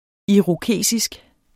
Udtale [ iʁoˈkeˀsisg ]